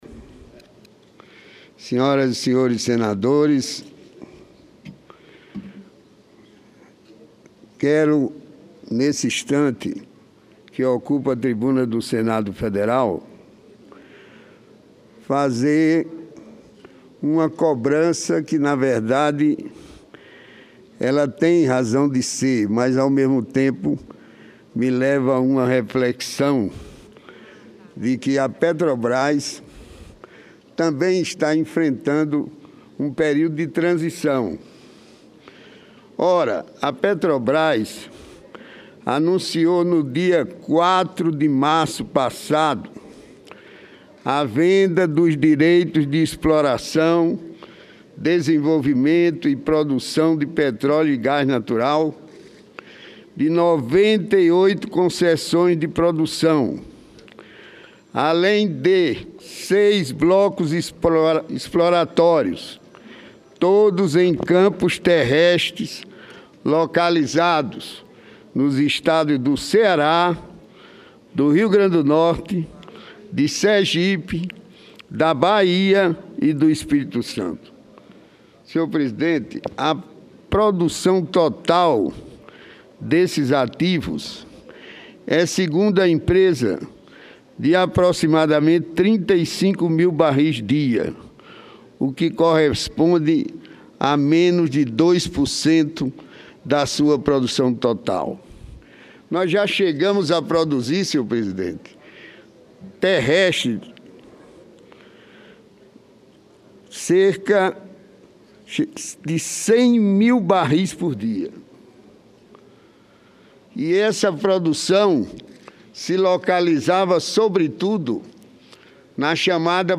Plenário
Discursos